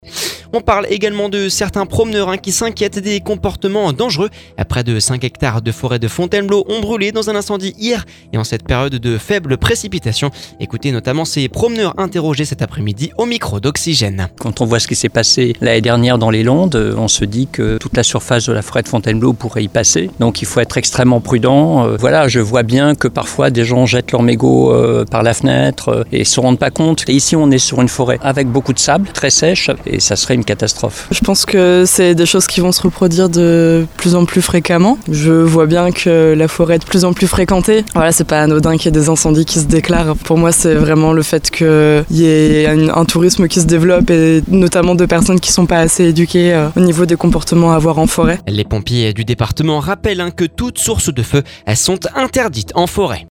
Écouter le podcast Télécharger le podcast Près de cinq hectares de la forêt de Fontainebleau brûlés dans un incendie hier en cette période faible en précipitation. Les pompiers rappellent que toutes sources de feu sont interdites en forêt. Ecoutez ces promeneurs interrogés cet après-midi au micro d’Oxygène…